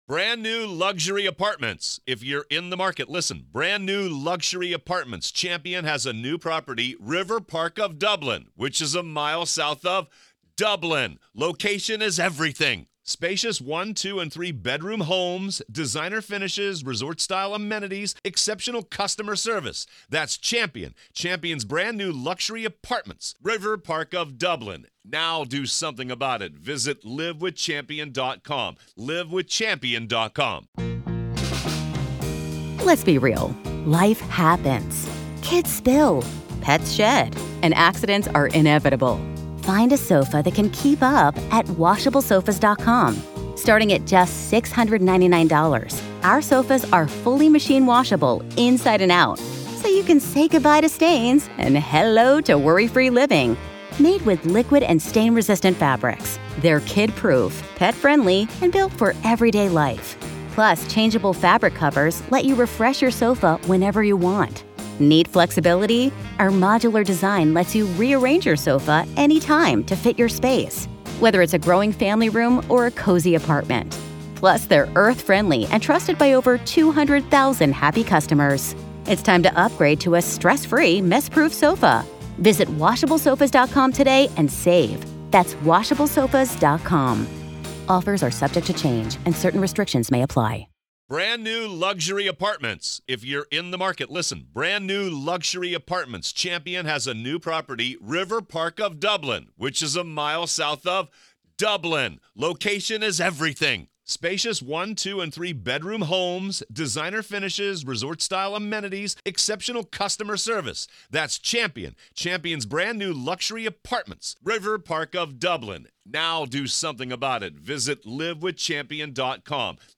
Listen to the full courtroom trial coverage of Alex Murdaugh. This is our continuing coverage of the Alex Murdaugh murder trial.